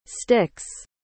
Como se pronuncia sticks?
sticks.mp3